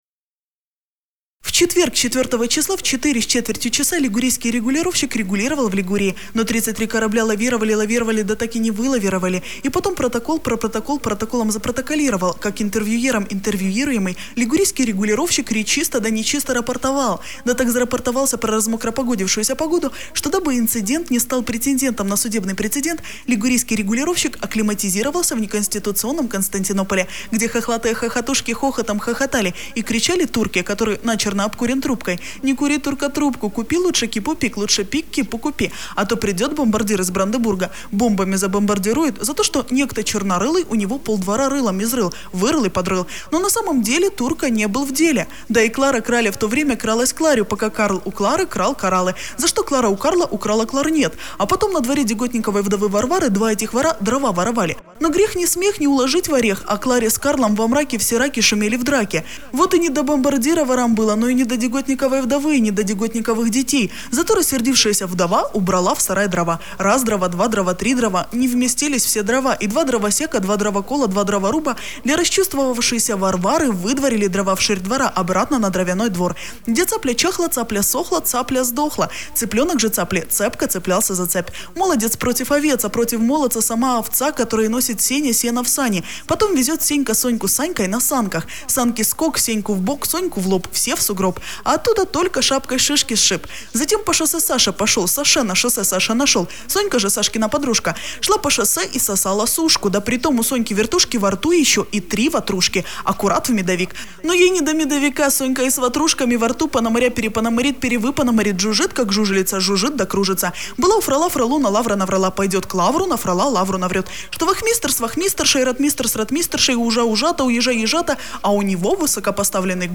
Tongue twister Liguria (Female).mp3